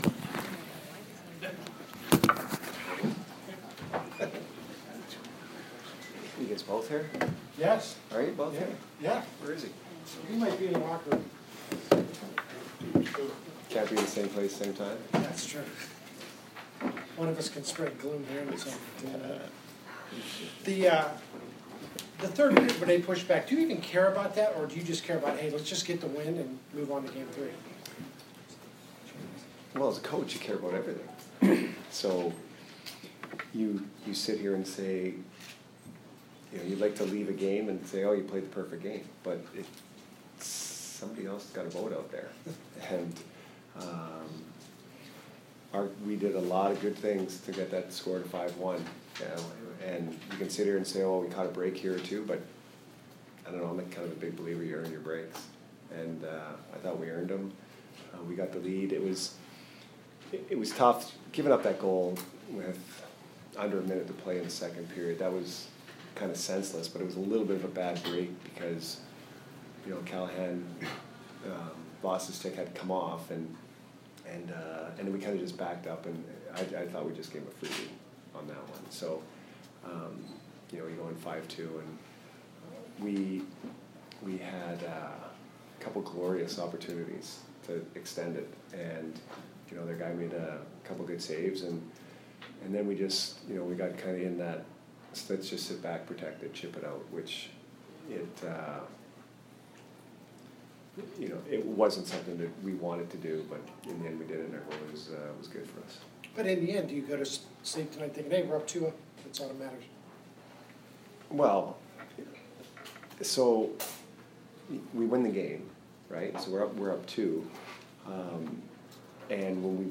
Jon Cooper post-game 4/14